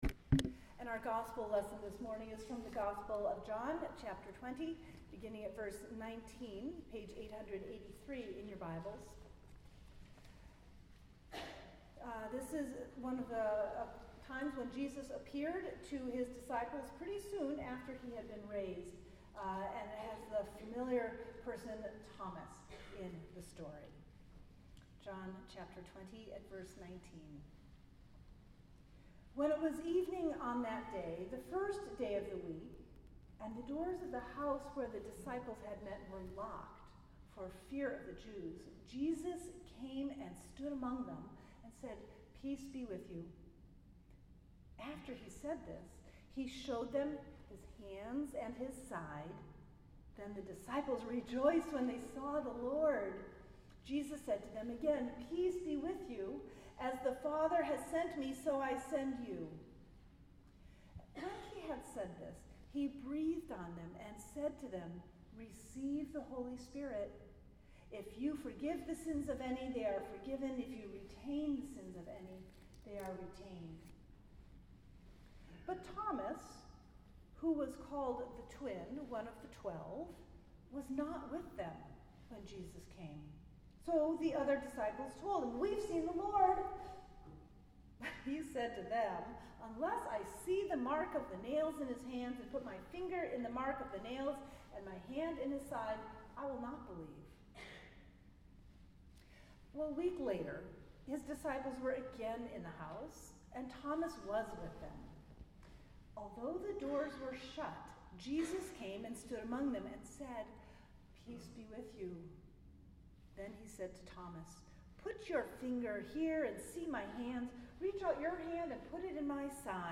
Sermons at Union Congregational Church
April 8, 2018 Second Sunday of Easter